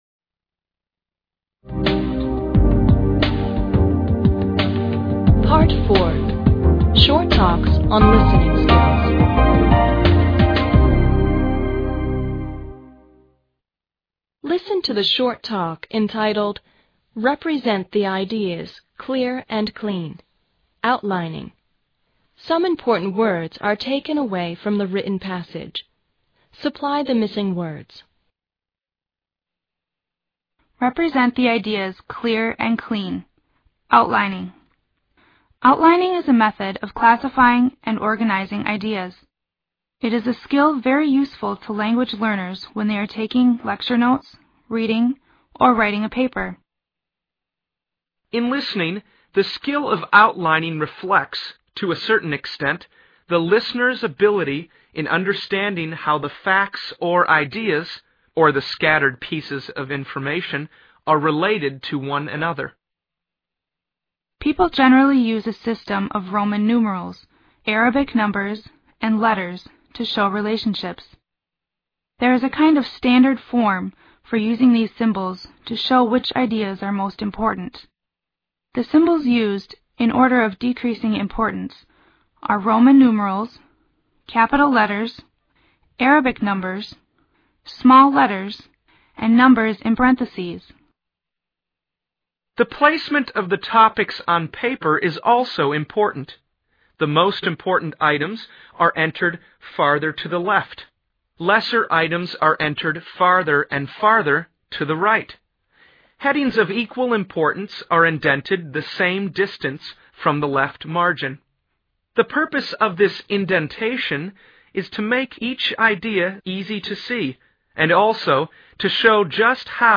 Part 4. Short talks on listening skills.